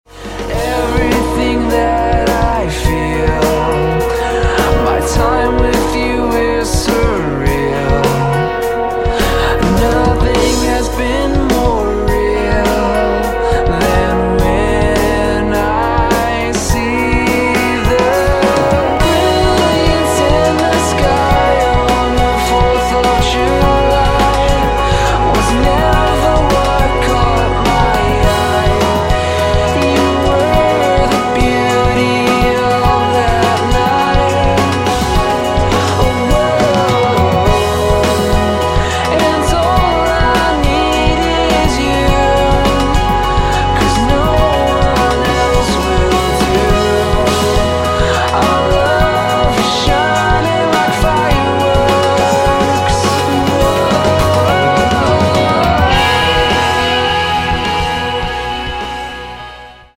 drums
vocalist
who plays lead guitar, does some singing
who sings and plays acoustic guitar and piano